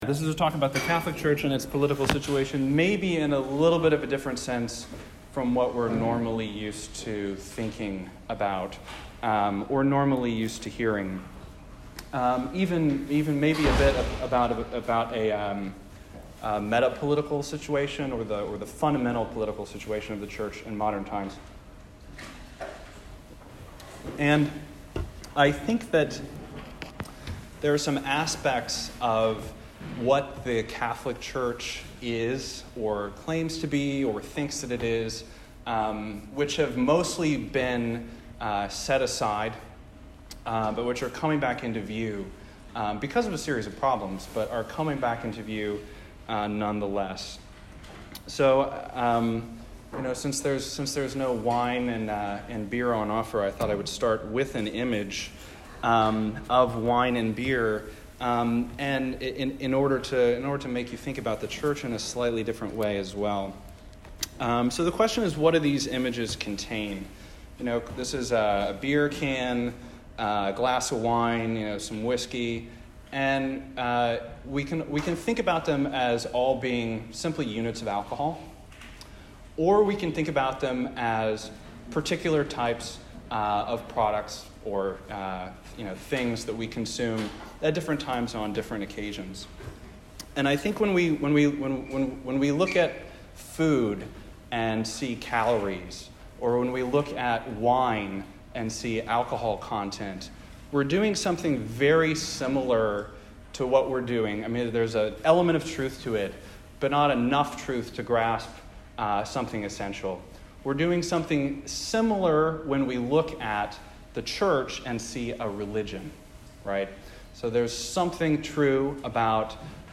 This lecture was given at the Yale Law School on 7 October 2019.